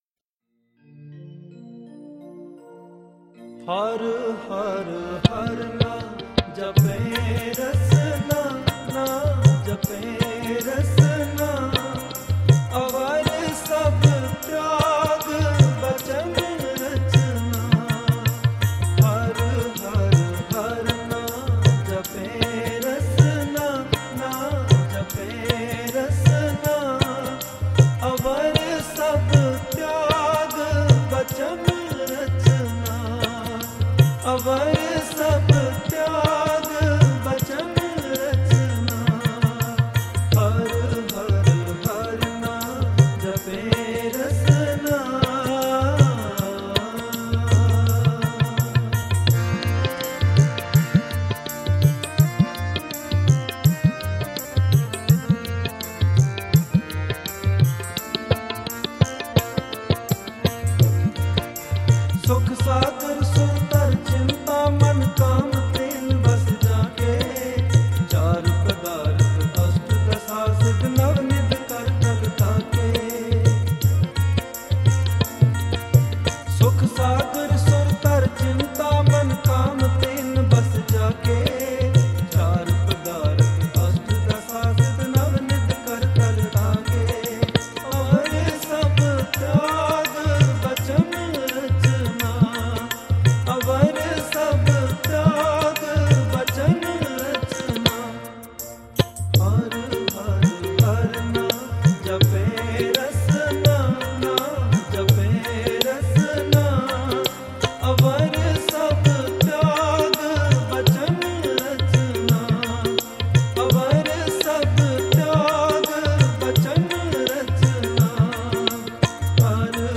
Genre: Shabad Gurbani Kirtan